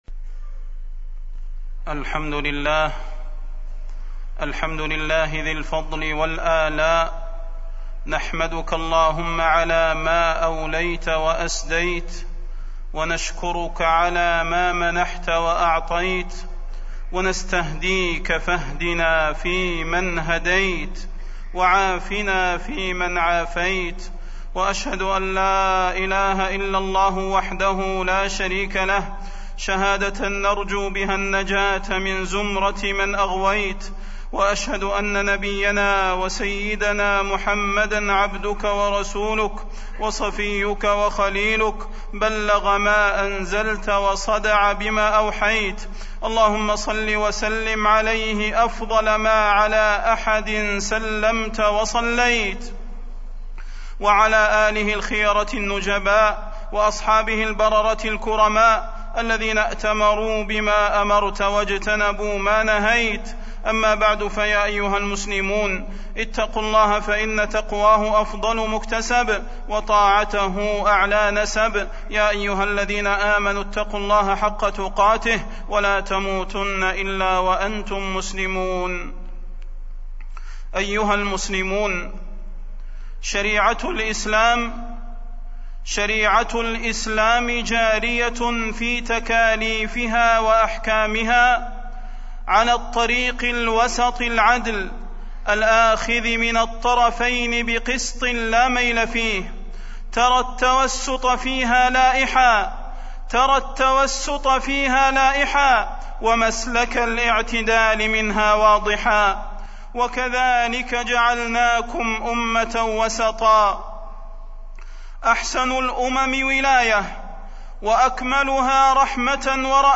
تاريخ النشر ٢٣ ربيع الأول ١٤٢٧ هـ المكان: المسجد النبوي الشيخ: فضيلة الشيخ د. صلاح بن محمد البدير فضيلة الشيخ د. صلاح بن محمد البدير الوسطية والإعتدال